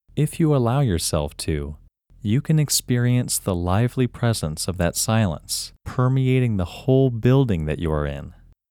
WHOLENESS English Male 8
WHOLENESS-English-Male-8.mp3